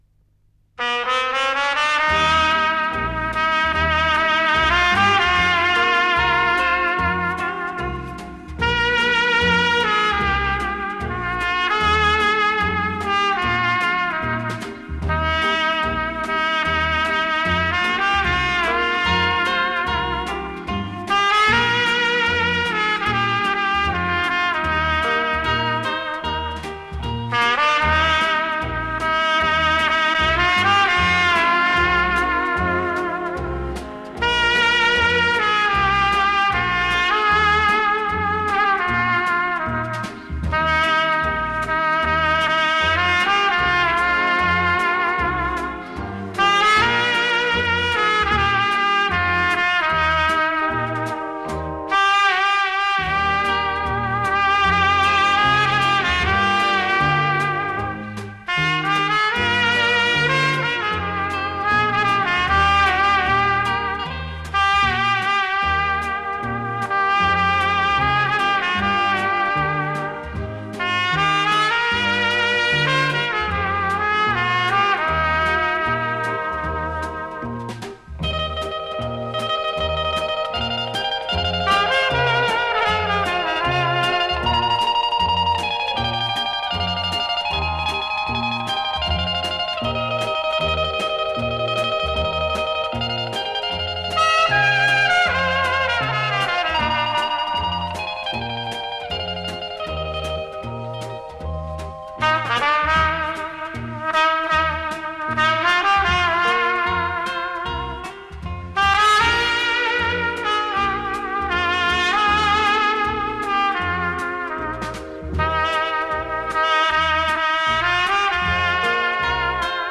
мр3 320 (моно)
Французский трубач, руководитель оркестра.